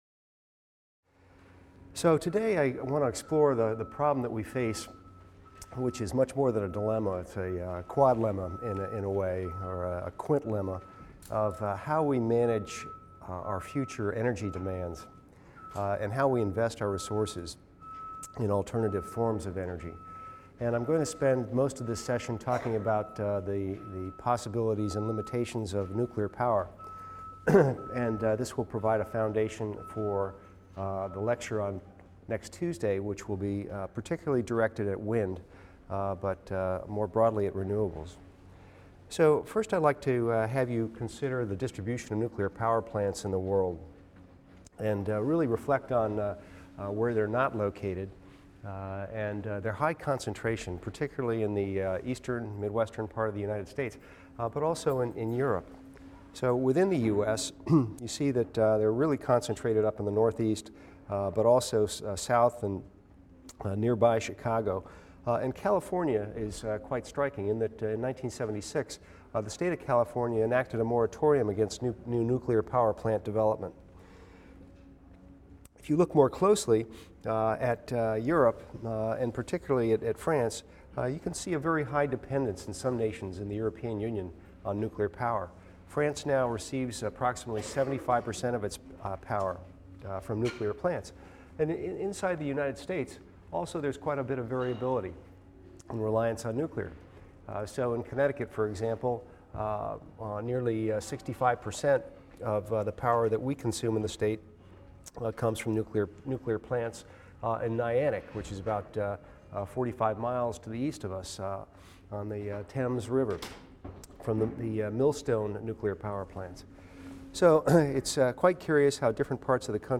EVST 255 - Lecture 22 - Past and Future of Nuclear Power | Open Yale Courses